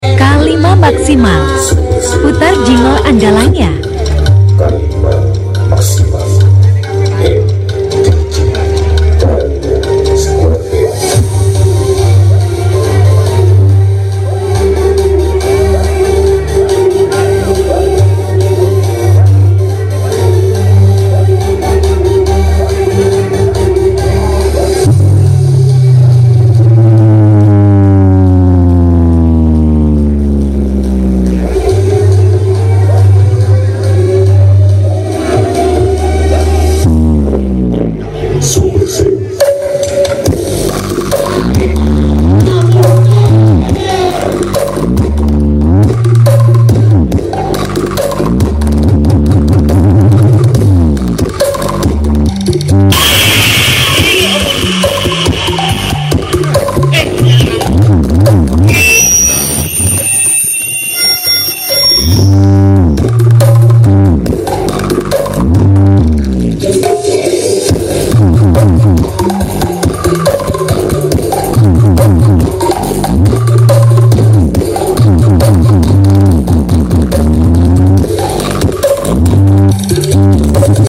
K5 maximal keluarkan bass gluduknya